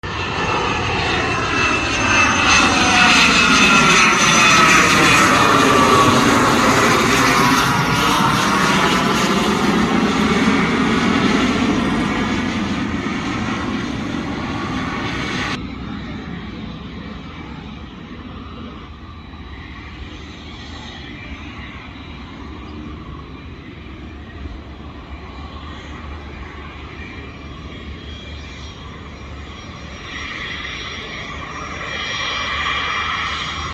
Citation Jet CJ1 landing Augsburg sound effects free download